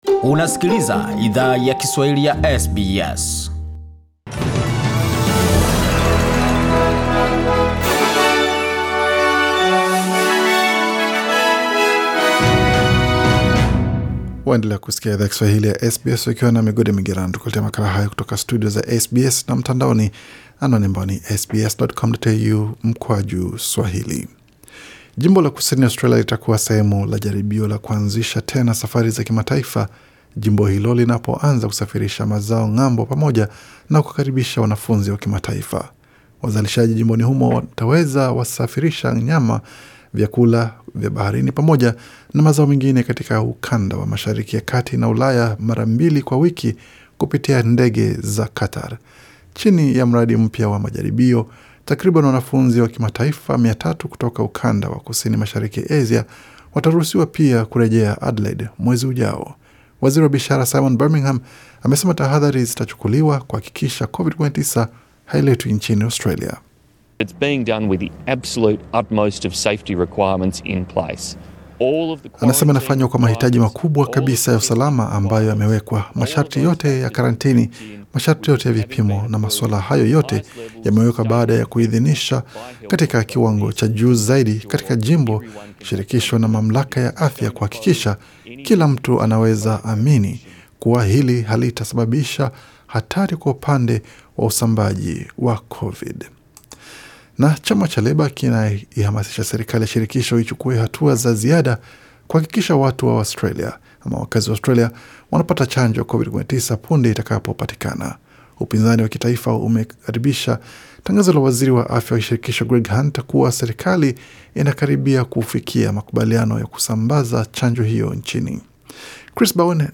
Taarifa ya habari 16 Agosti 2020